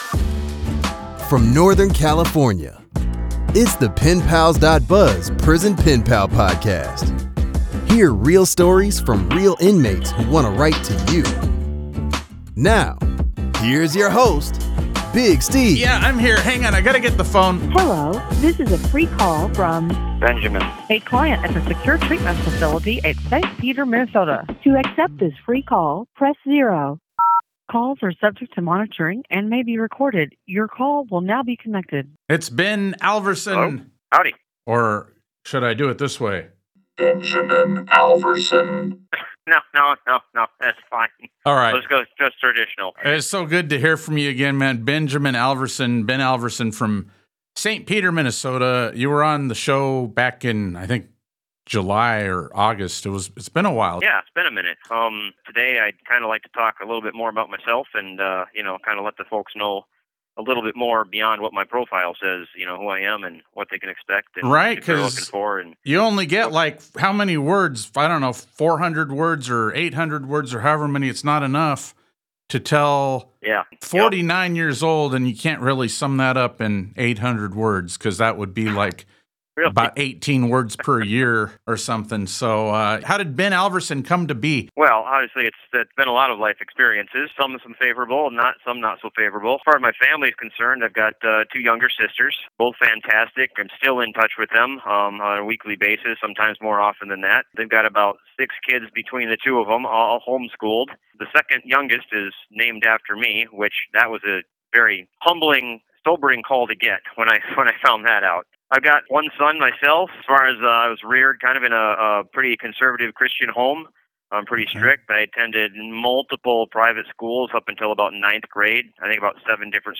Prison Artist: An Interview